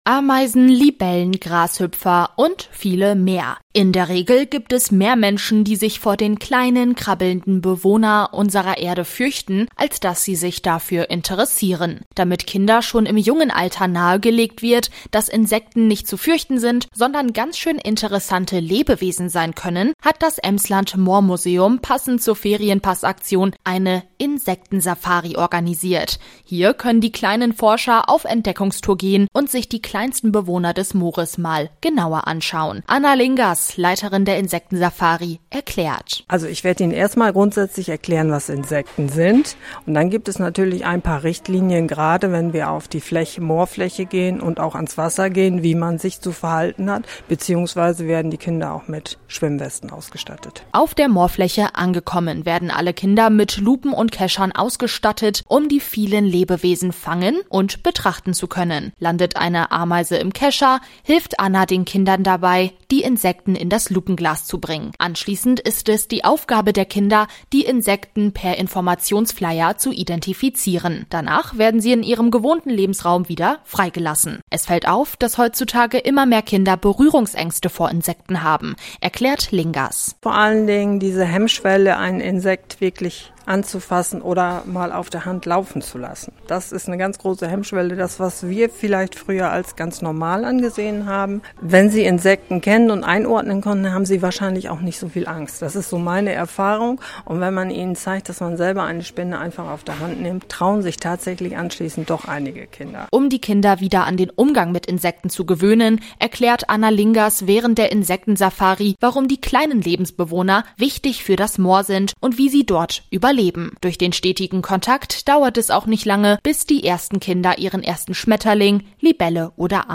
Damit die Berührungsangst gegenüber Insekten bei Kindern sinkt, führte das Emsland Moormuseum am vergangenen Mittwoch eine Insektensafari durch.